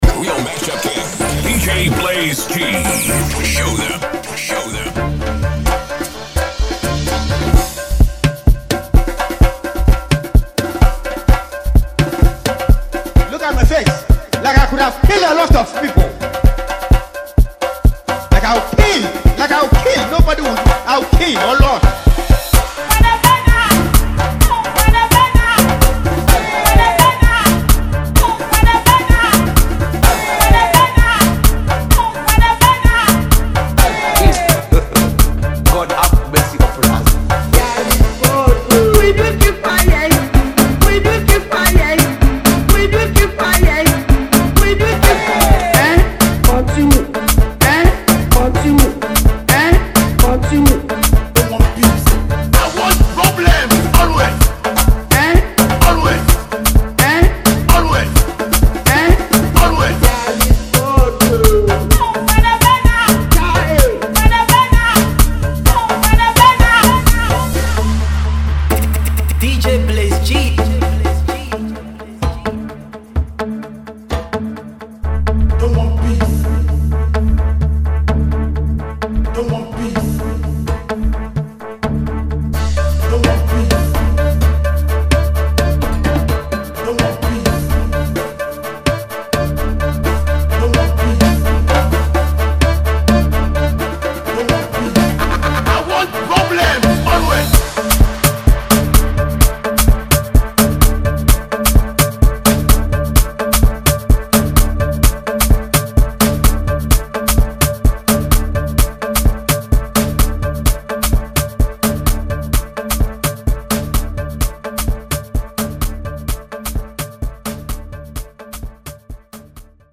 and this free mp3 download is a jam for the dance floor.
mash-up